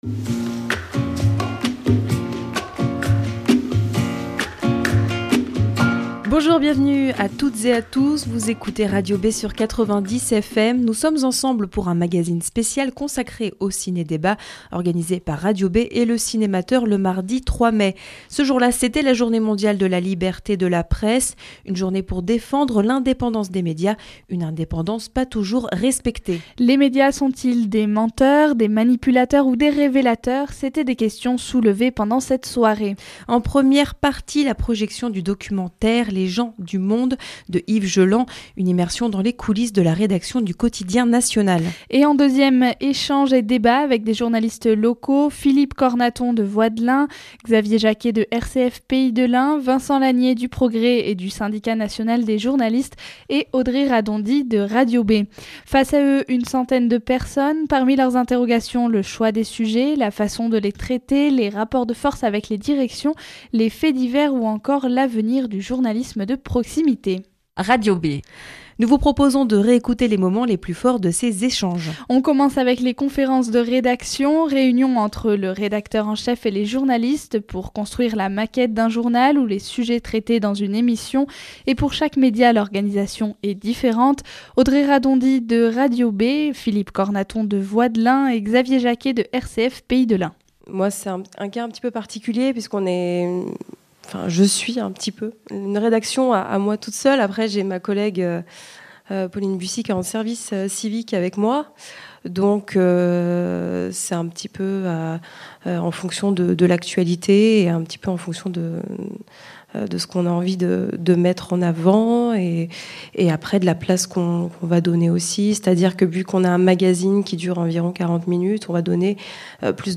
Mardi 3 mai, Radio B organisait, en partenariat avec Le Cinémateur, un ciné-débat autour des médias.